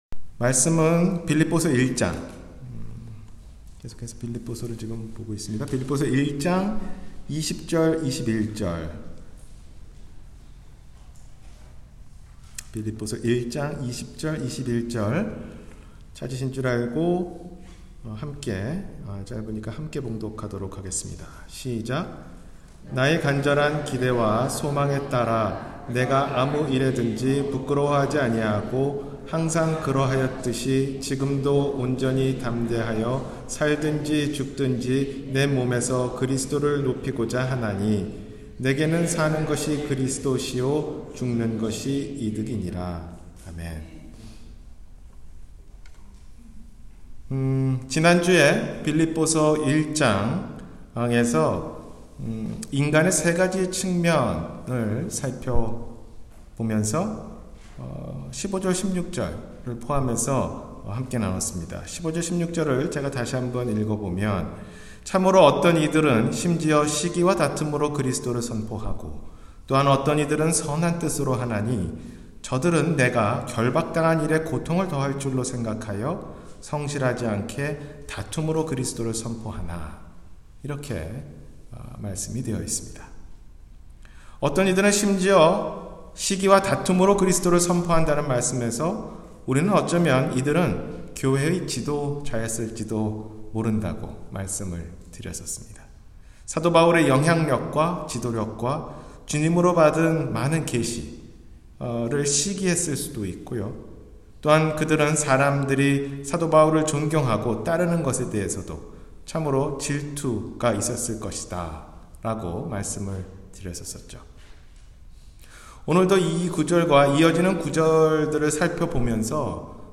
있을 때 감사하자 – 주일설교